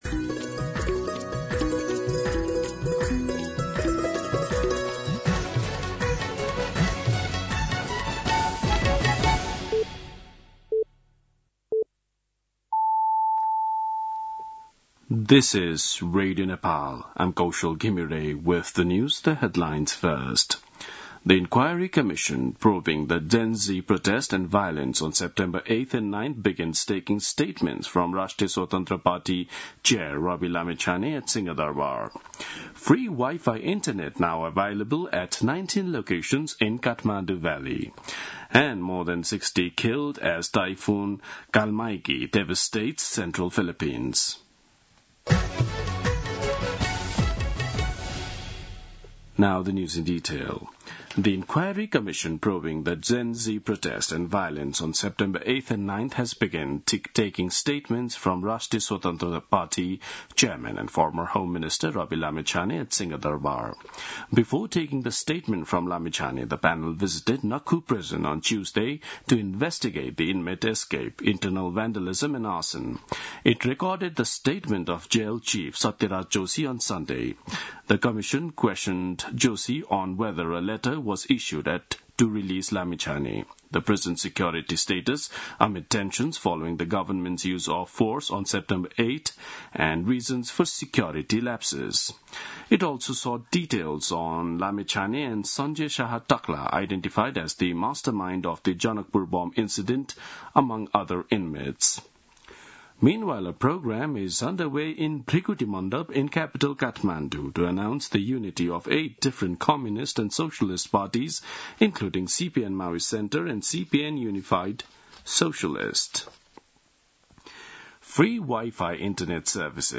दिउँसो २ बजेको अङ्ग्रेजी समाचार : १९ कार्तिक , २०८२
2-pm-English-News-1.mp3